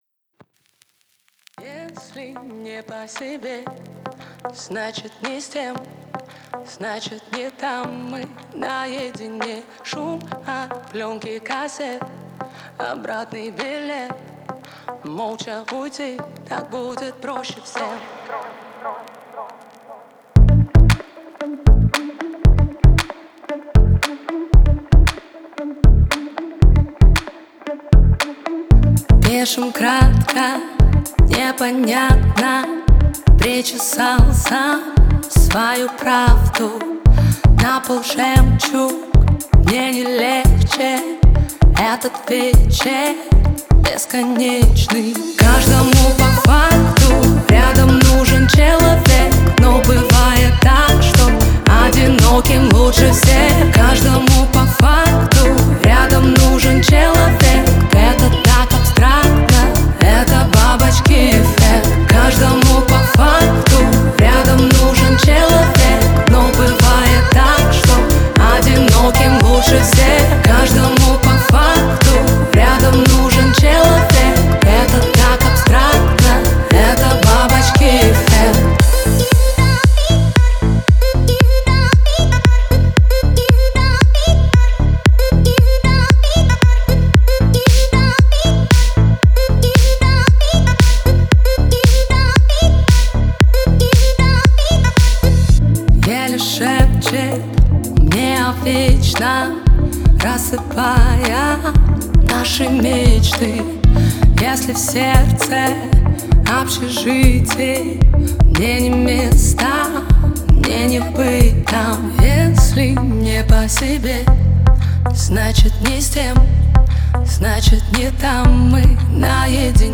Русская поп музыка